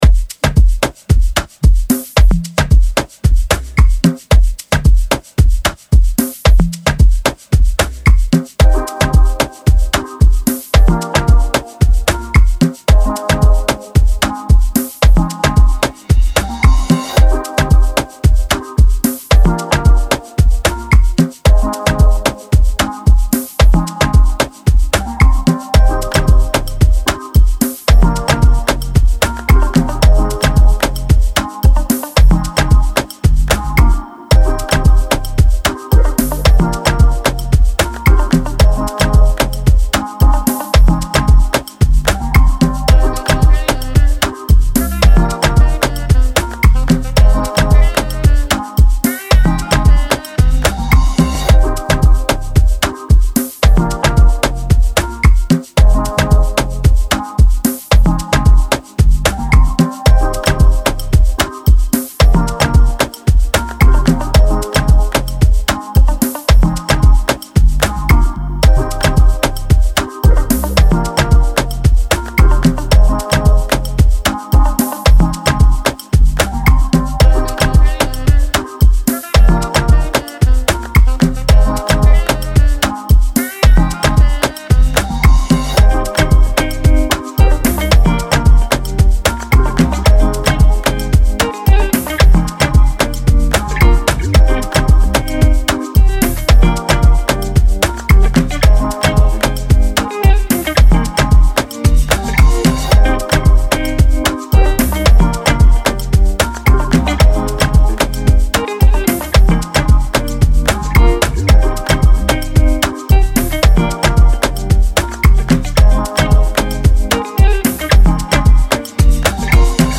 Afrobeat
C Minor